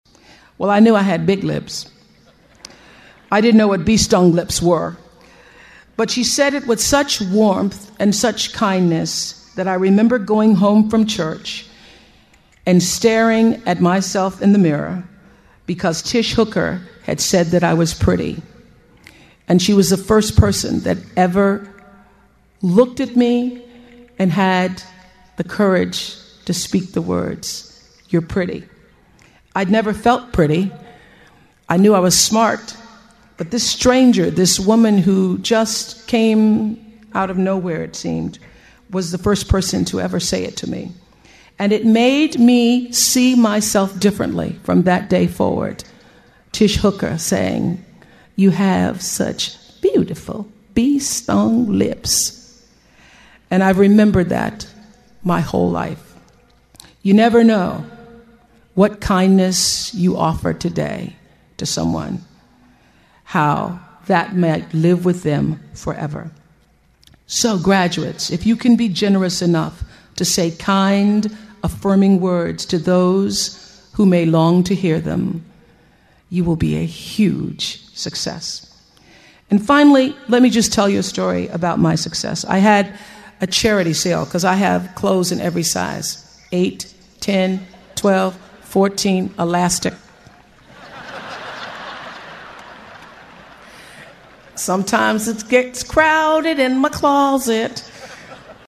名校励志英语演讲 82:追随自己的心声,你们一定会成功 听力文件下载—在线英语听力室
借音频听演讲，感受现场的气氛，聆听名人之声，感悟世界级人物送给大学毕业生的成功忠告。